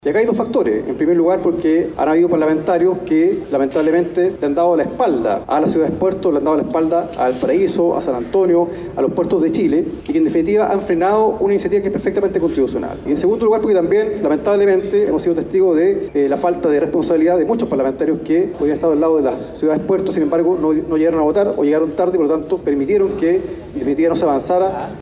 Por otro lado, el parlamentario comunista Luis Cuello, que impulsó el proyecto, apuntó contra los diputados que no llegaron a votar la iniciativa.